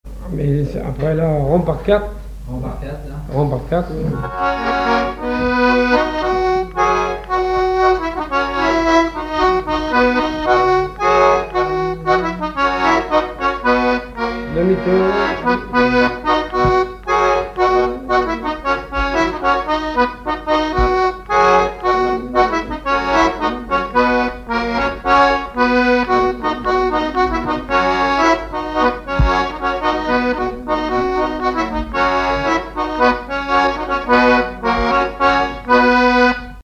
danse : quadrille
accordéon diatonique
Pièce musicale inédite